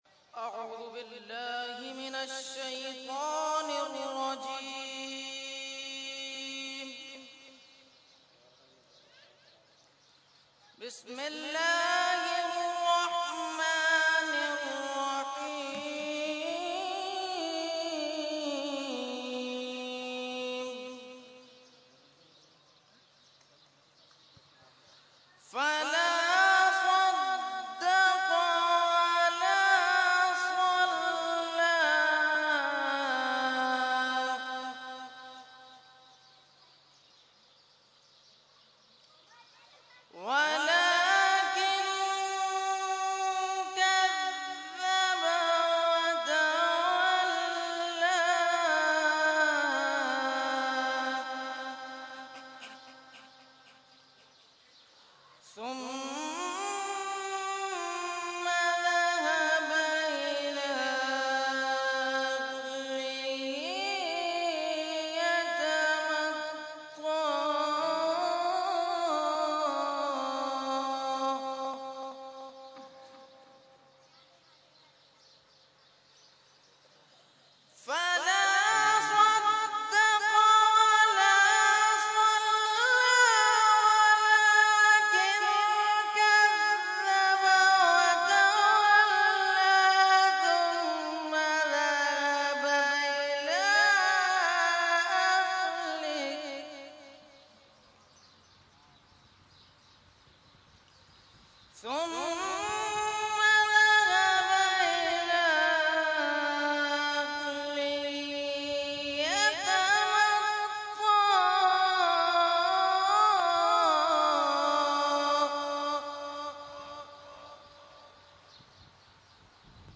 Qirat – Urs e Qutb e Rabbani 2013 Day1 – Dargah Alia Ashrafia Karachi Pakistan